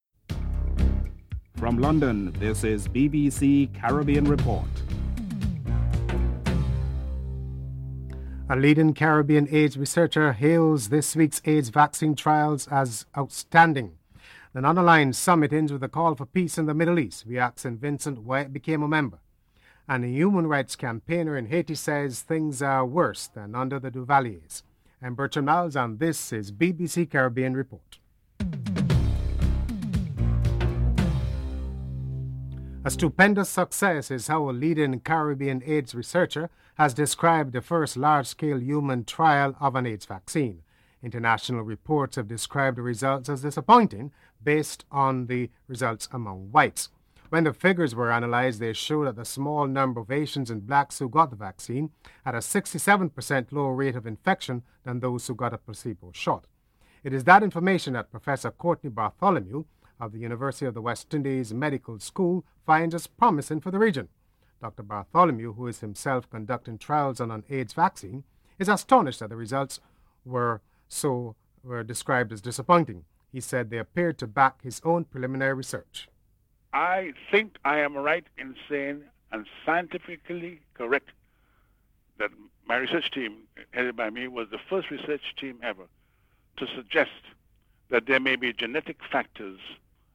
1. Headlines